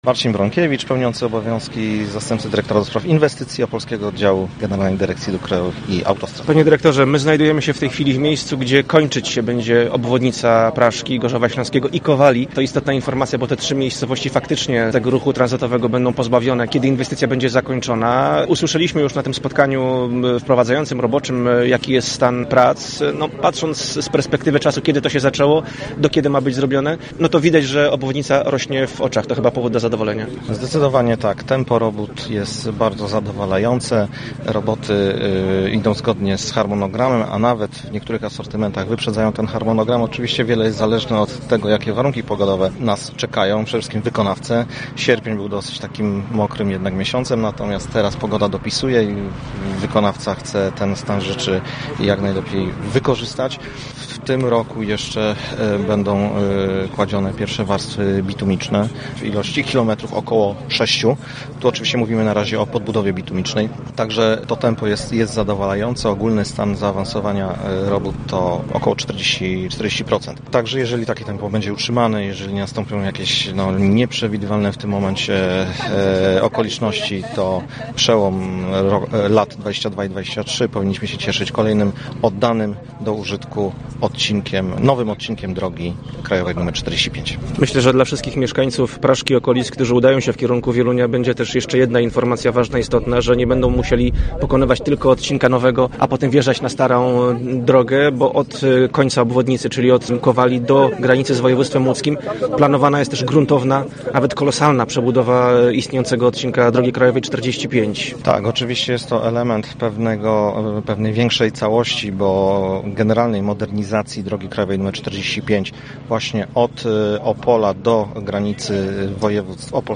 Jeszcze w tym roku na odcinku około sześciu kilometrów położony zostanie dywanik z masy bitumicznej. Dziś GDDKiA przy współudziale wykonawcy – firmy Mostostal, zorganizowała wizytę studyjną na budowie drogi, w której udział wzięli przedstawiciele mediów z województwa opolskiego oraz Radio Ziemi Wieluńskiej.